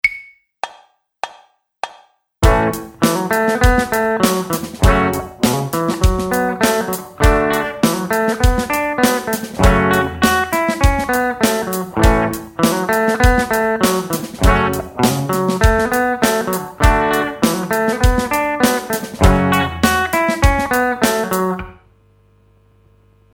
While this example uses the A major and F# minor chords to play with the A major pentatonic scale.